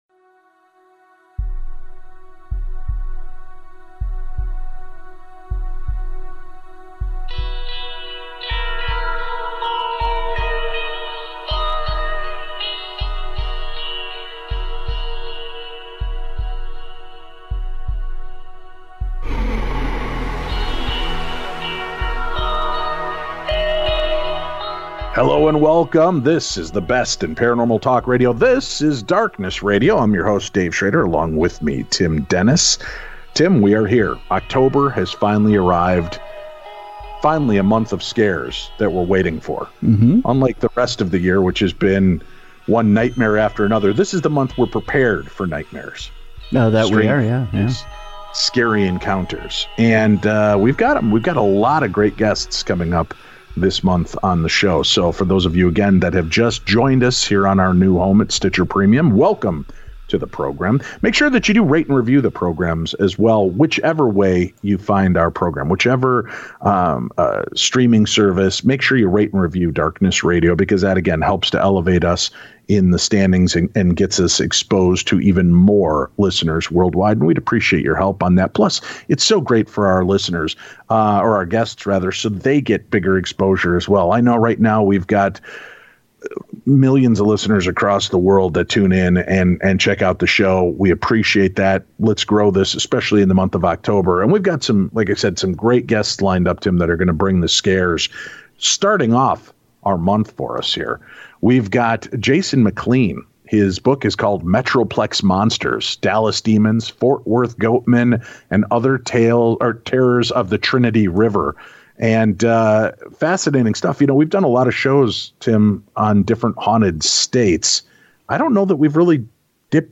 Transcript Click on a timestamp to play from that location 0:00.0 Hello and welcome. 0:26.4 This is the best in paranormal talk radio.